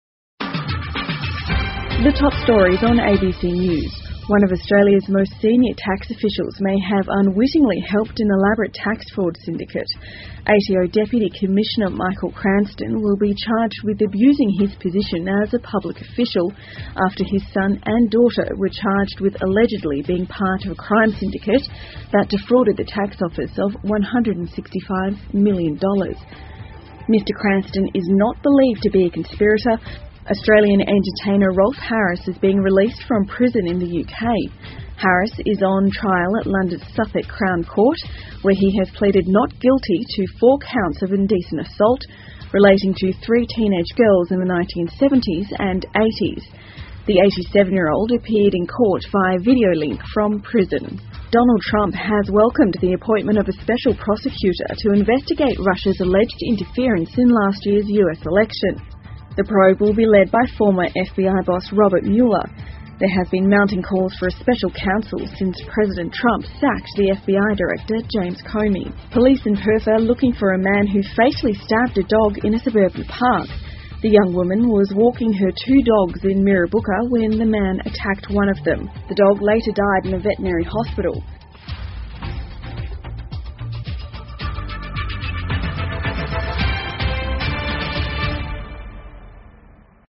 澳洲新闻 (ABC新闻快递) 澳大利亚曝税务欺诈大案 FBI前局长领导通俄门调查 听力文件下载—在线英语听力室